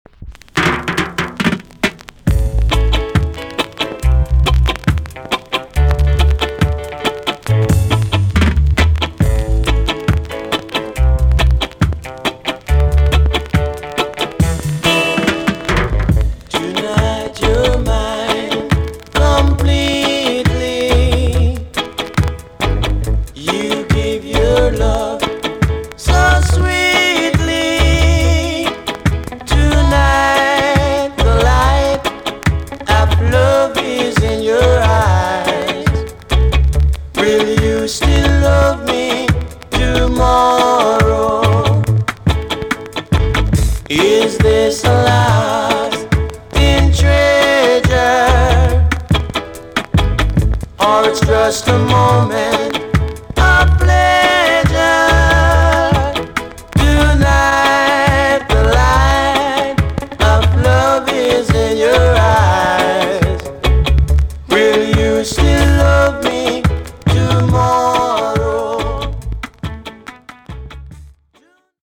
TOP >REGGAE & ROOTS
VG+ 軽いチリノイズがあります。